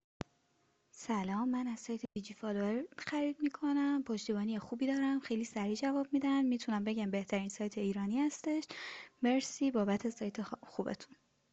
نظرات مشتریان عزیزمون با صدای خودشون